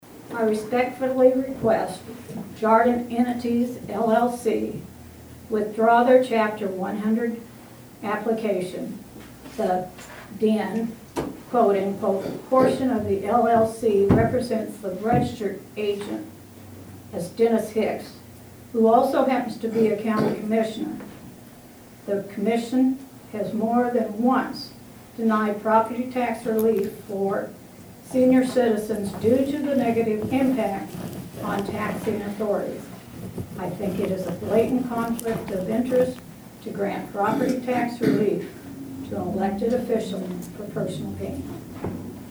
Fourth Ward Councilwoman Pam Jarding expressed her concerns and read from a comment sent to her.